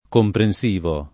[ kompren S& vo ]